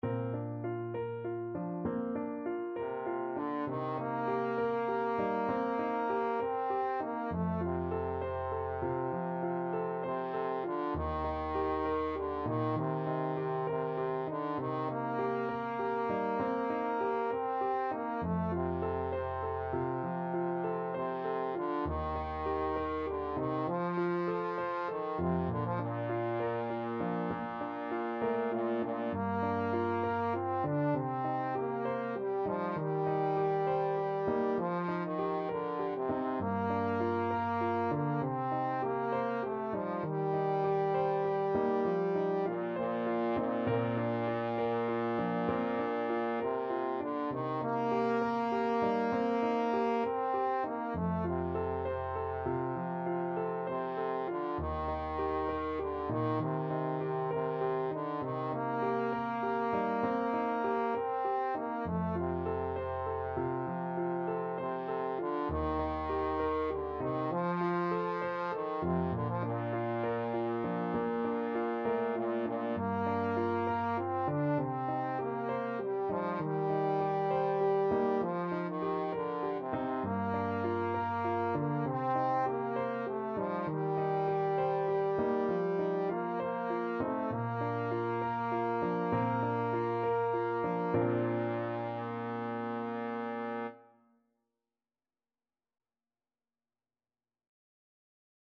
Classical (View more Classical Trombone Music)